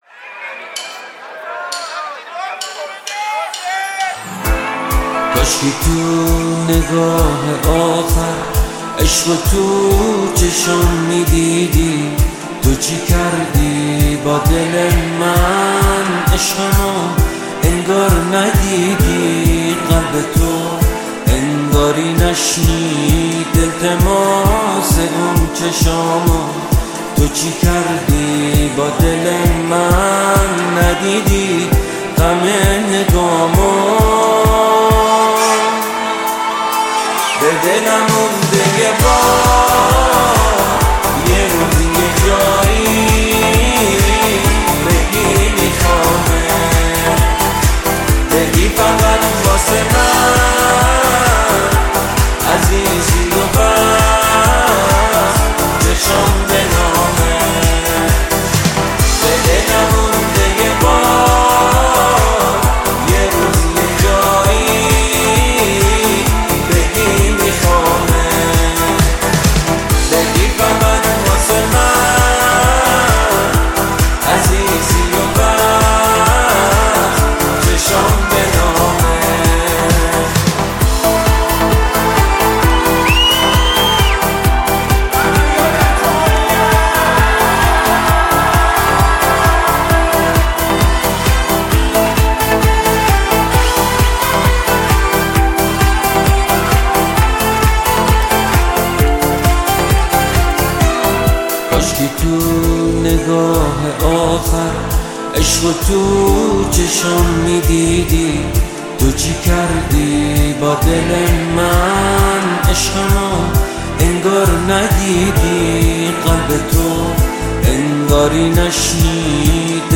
موسیقی پاپ
عاشقانه